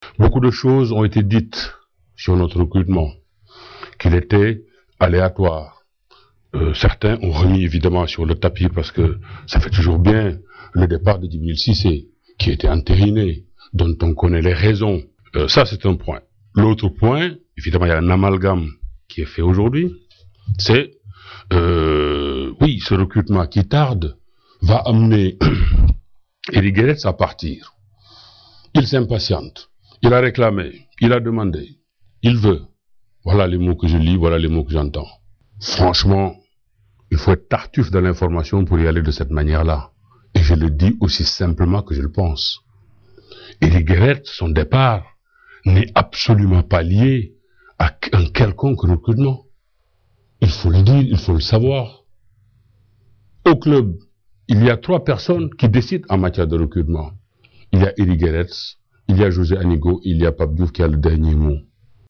Pour ceux qui ne l’ont pas connu, parmi les plus jeunes fans de l’OM, ou les autres qui n’ont jamais entendu s’exprimer Pape Diouf dans son rôle de président de l’OM, voici quelques-unes de ses interventions parmi les plus savoureuses devant la presse à La Commanderie.